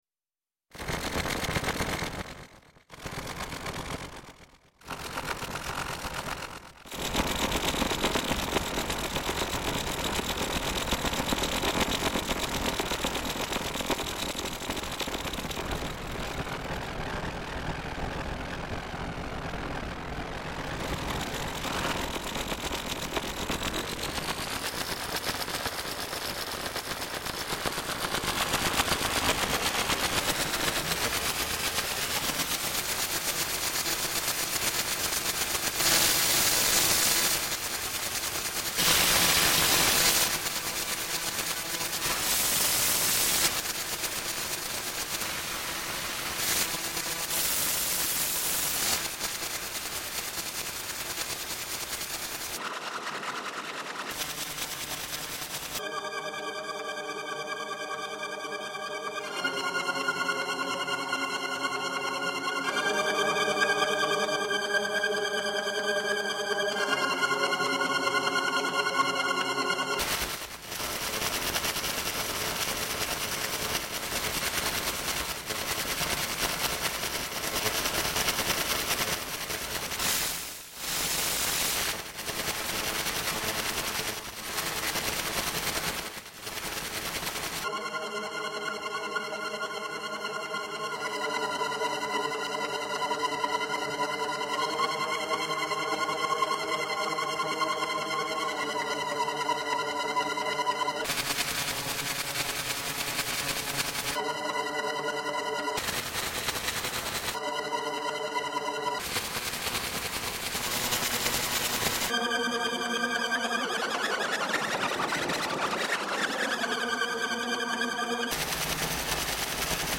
Ein Ambient Track, erstellt mit Ableton Studio 9.